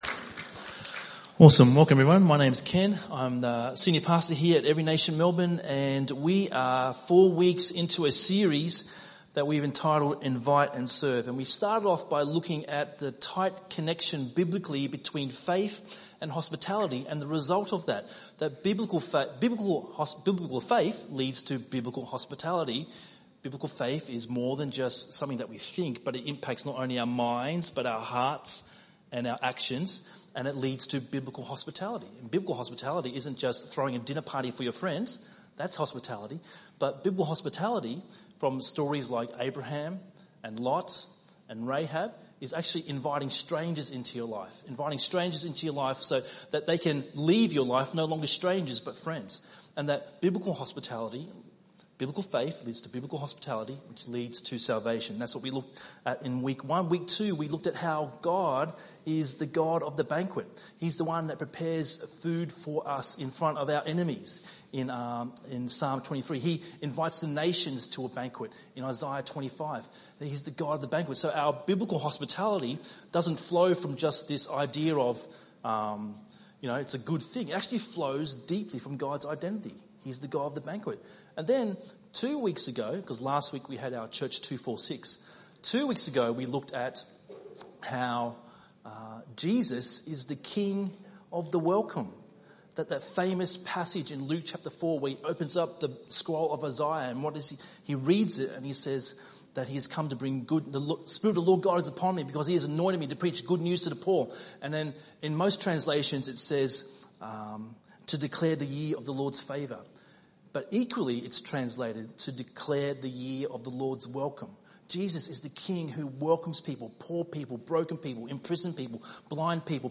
by enmelbourne | Nov 11, 2019 | ENM Sermon